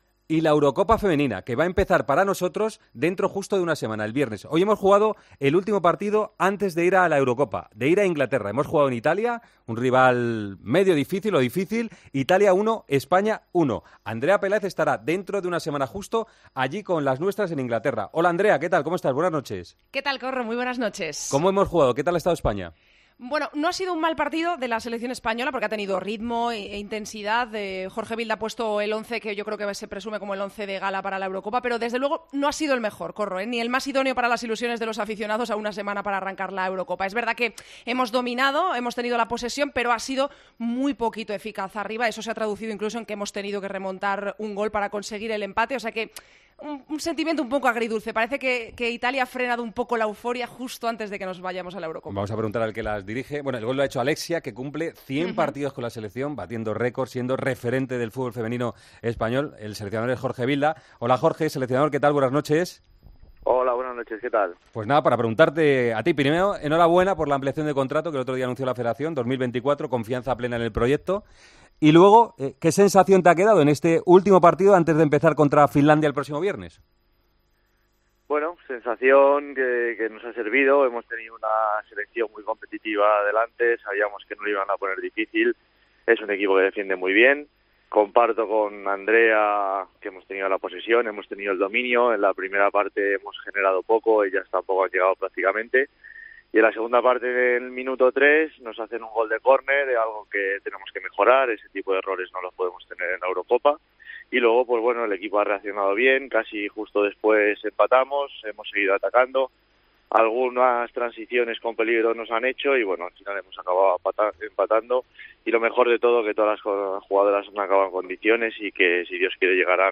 El seleccionador femenino de fútbol habla de "una convocatoria compensada con posibilidades en todas las posiciones" y "somos aspirantes al título igual que otras selecciones"